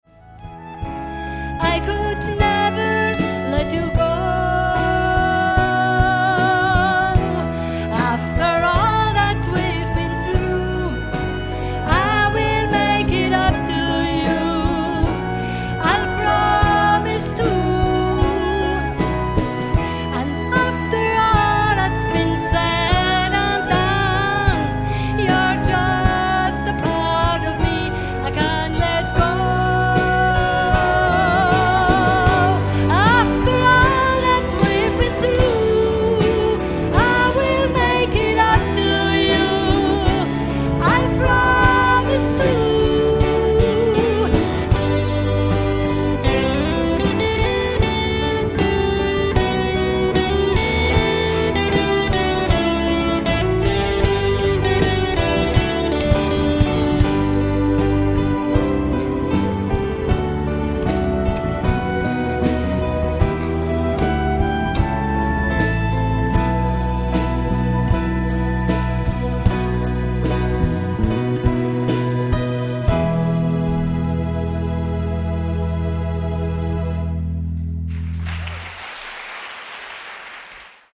Jubiläumskonzert